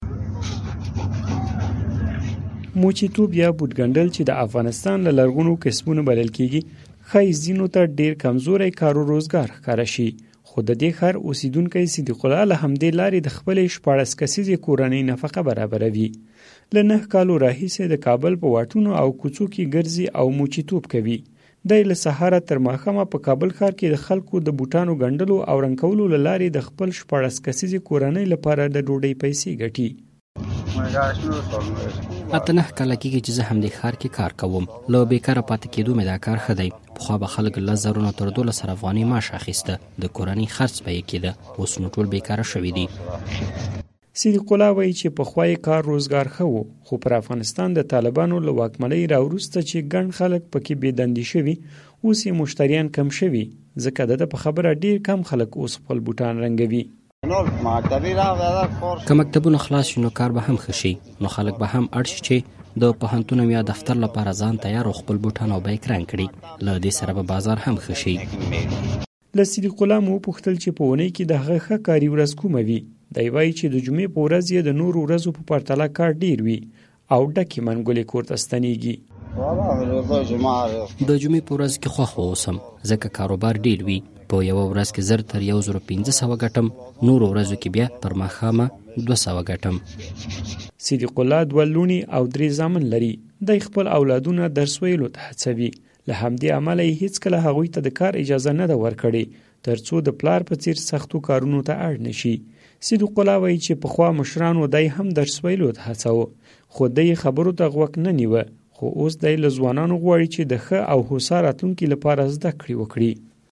د موچي راپور